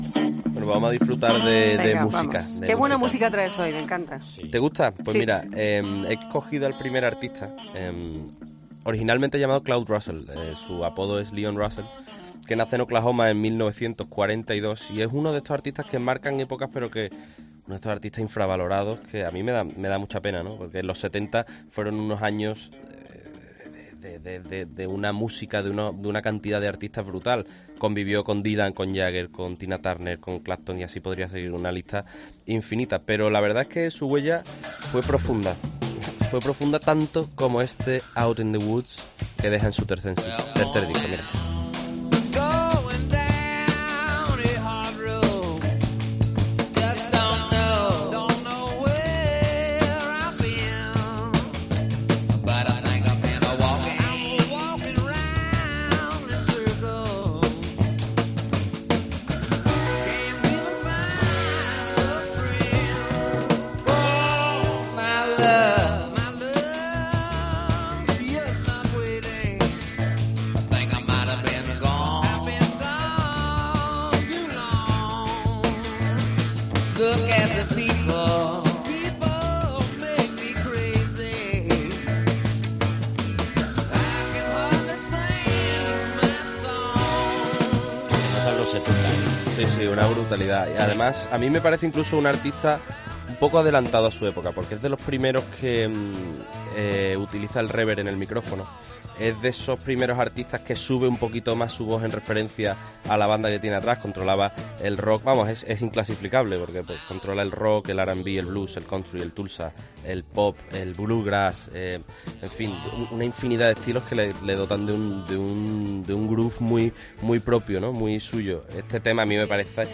Cada vez que aparece en directo... sorprende con lo último en tecnología.